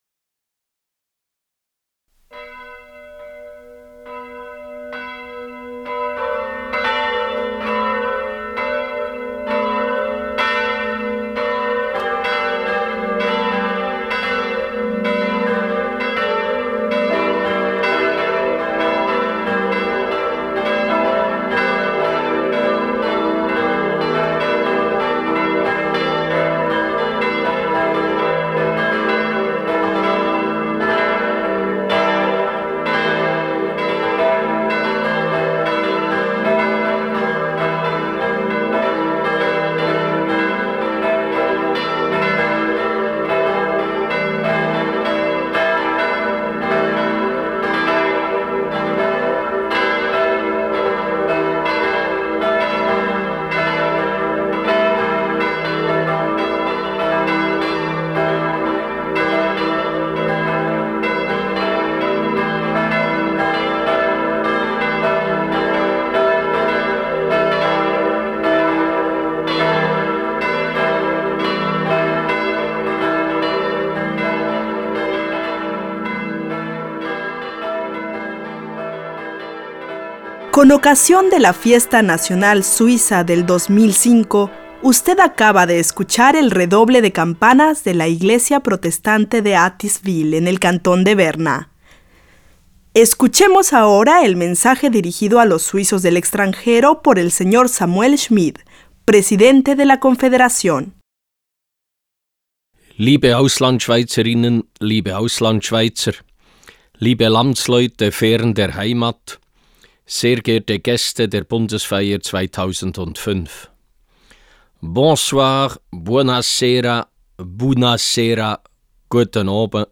Discurso del presidente de la Confederación Samuel Schmid a los suizos del exterior.